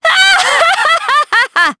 Nicky-Vox_Happy3_jp.wav